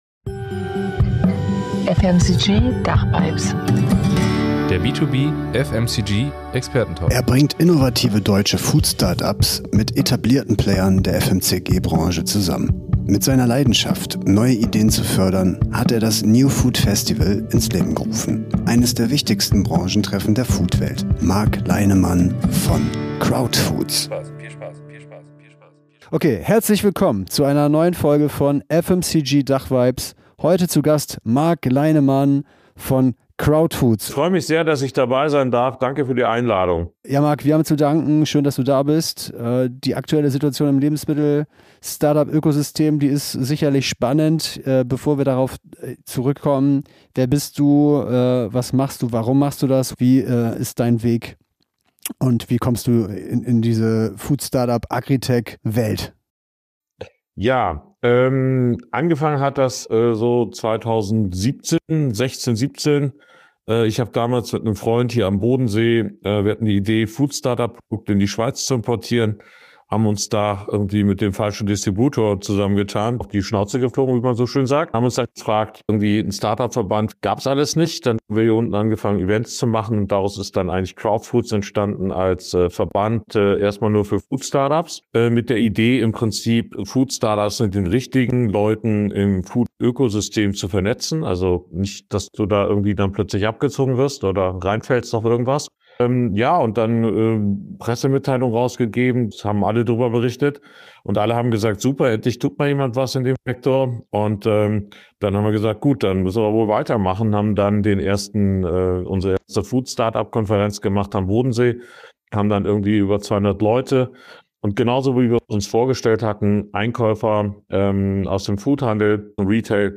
Expertentalk